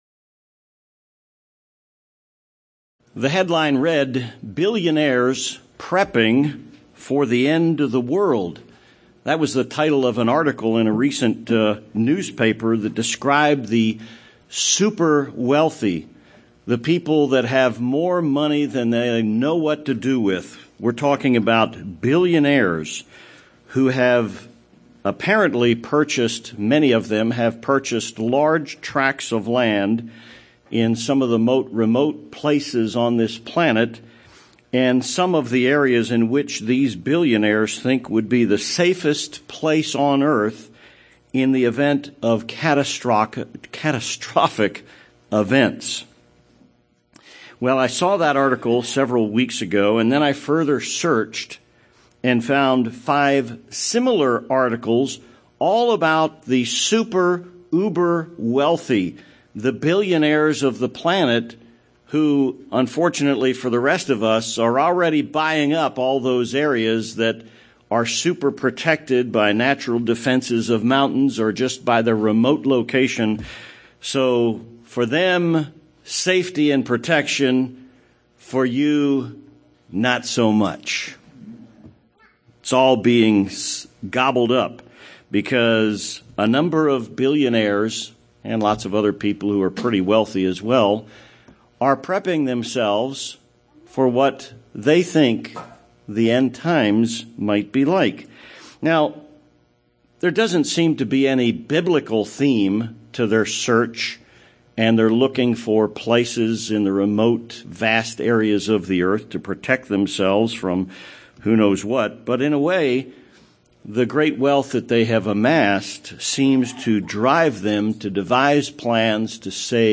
What led the Church of God to research the idea that God will provide a "place of safety" for His people? This sermon provides the background of the history of this teaching, but more importantly, what is really important for anyone living in the "end times"?